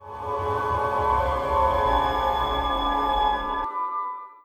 Samsung Galaxy S120 Startup.wav